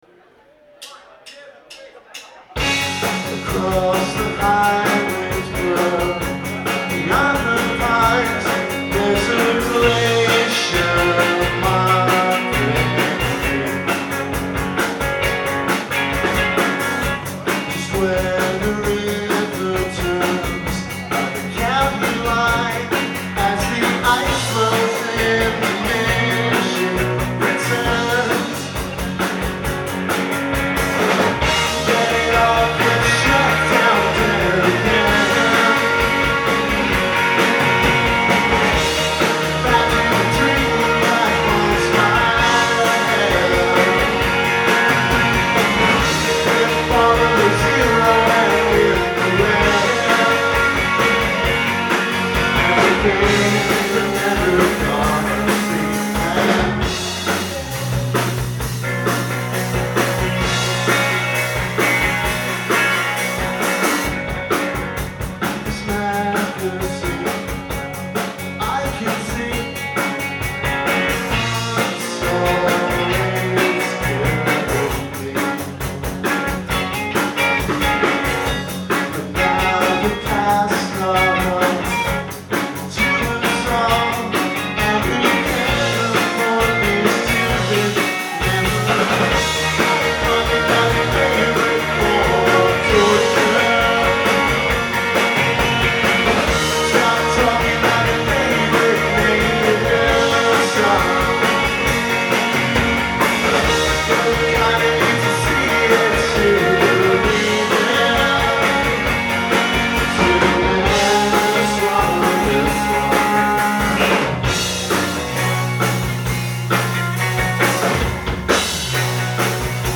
Live at the Middle East Downstairs